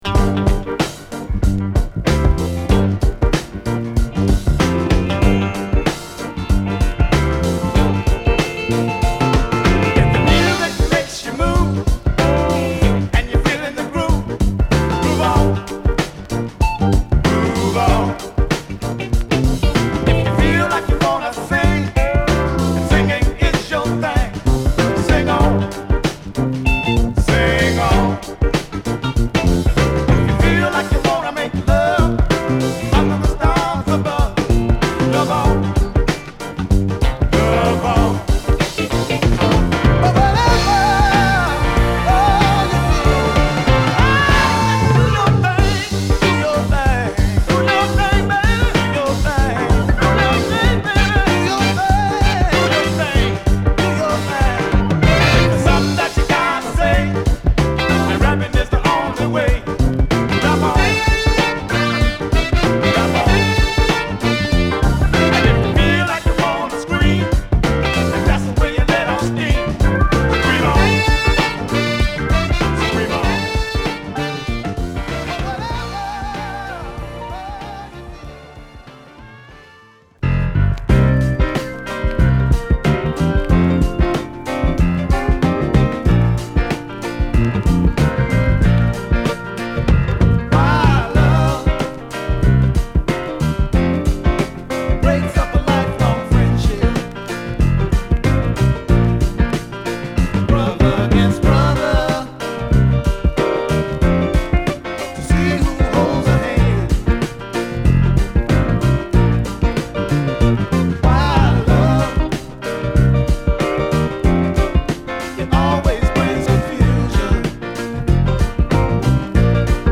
強めに刻むヌケの良いドラムとグルーヴィーなホーン/ピアノ等が絡む、熱いファンキーチューン！